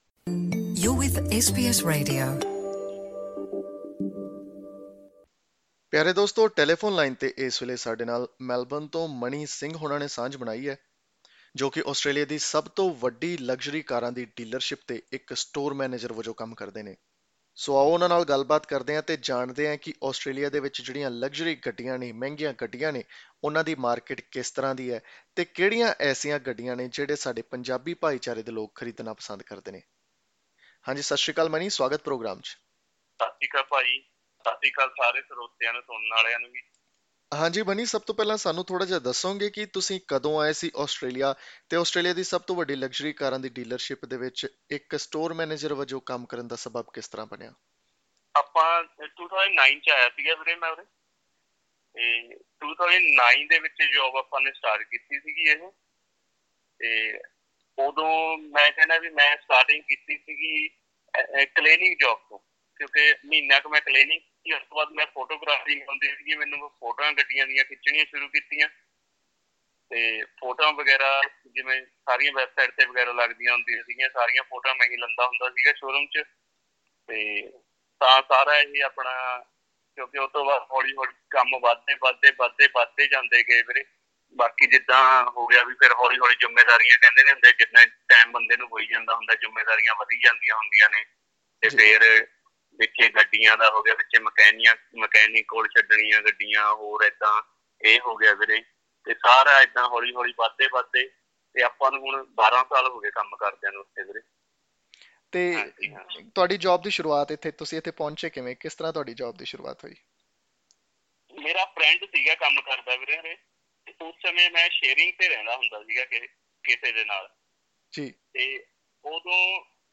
Listen to the full interview in Punjabi by clicking on the audio icon in the picture above.